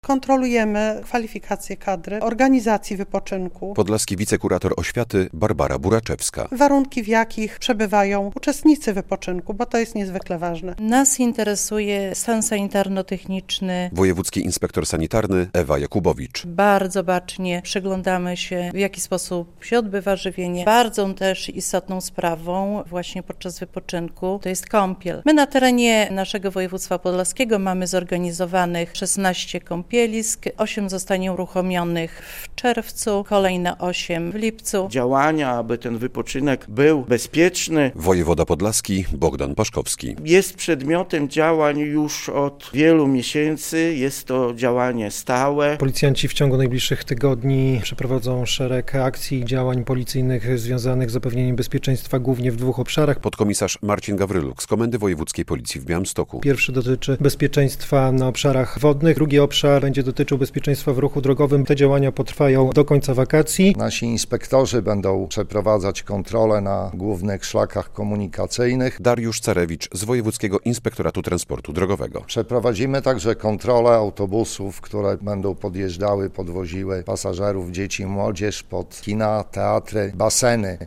Podlaskie władzy i służby apelują o dbanie o bezpieczeństwo dzieci - relacja
W poniedziałek (19.06) w Podlaskim Urzędzie Wojewódzkim odbyła się konferencja prasowa pod hasłem "Bezpieczne wakacje", w której wziął udział wojewoda, a także przedstawiciele różnych służb.